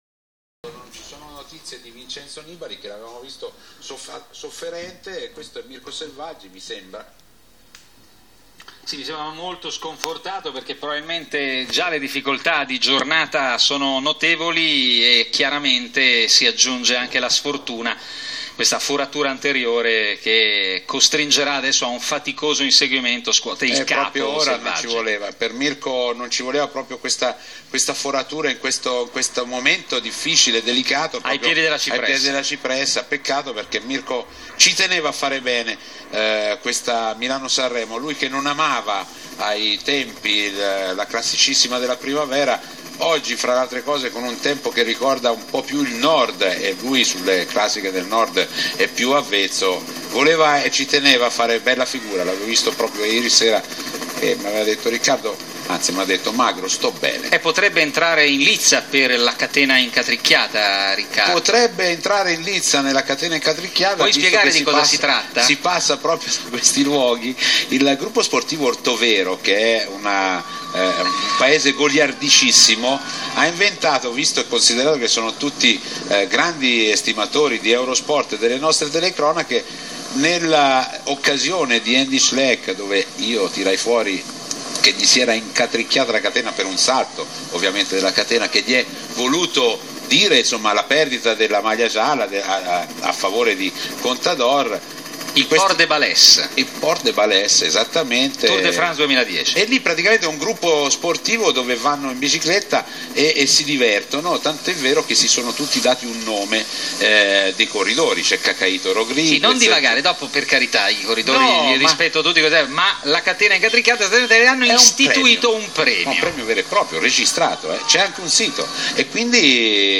Albo d'Oro News Video Contatti News Ascolta la presentazione ufficiale del Premio da parte di Andrea Berton e Riccardo Magrini durante la diretta della Milano Sanremo.